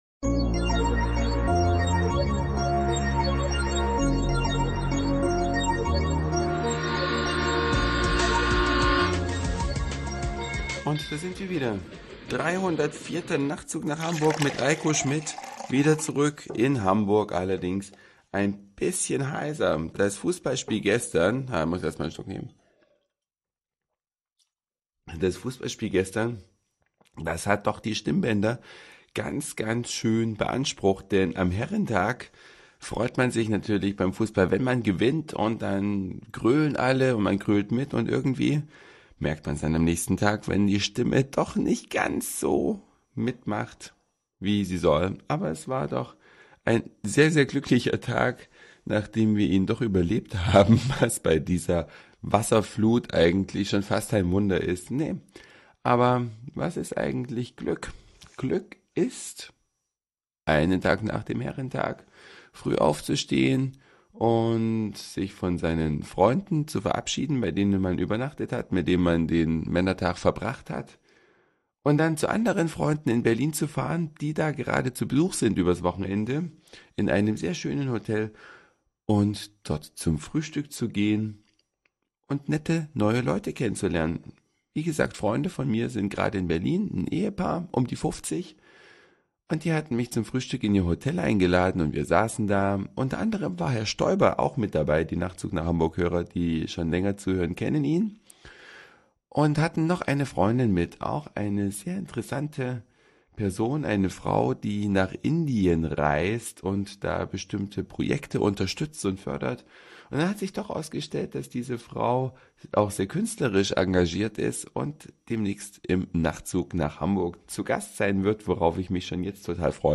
Die Stimme am Tag danach: die Auswirkungen von Freudenschreien